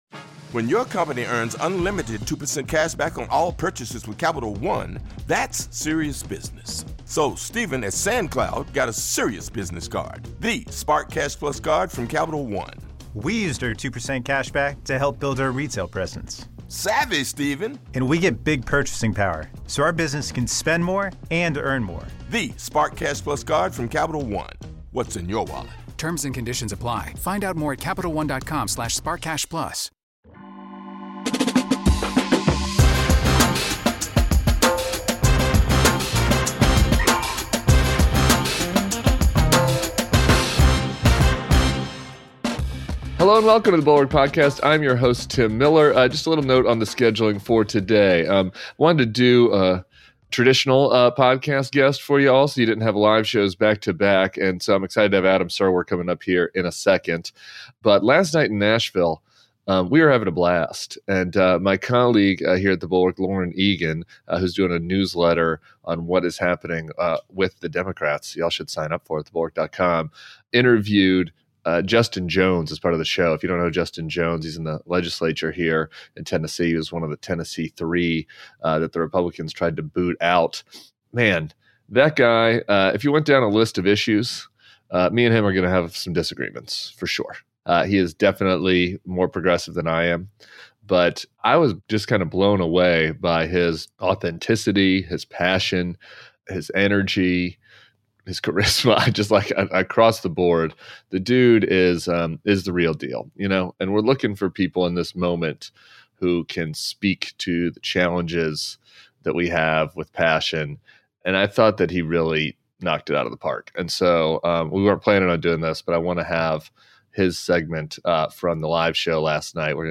The Atlantic's Adam Serwer joins Tim Miller for the weekend pod, with a side serving from our live Nashville show.